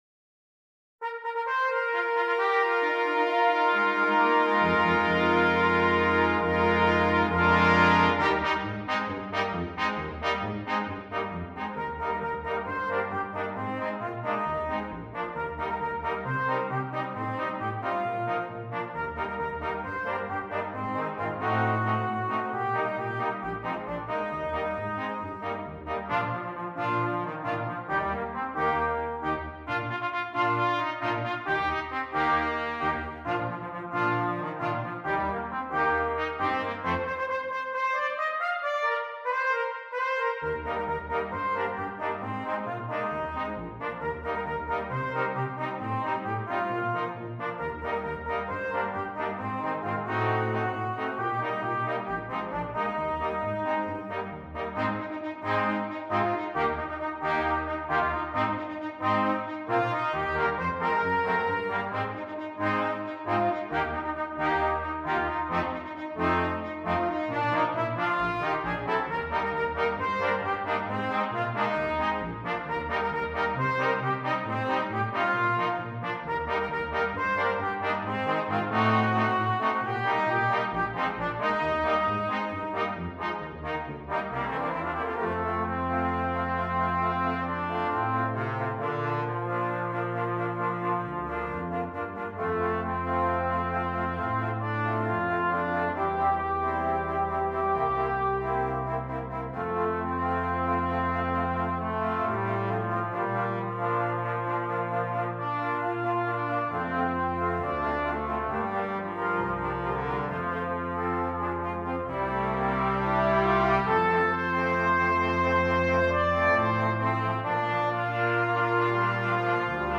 • Brass Quintet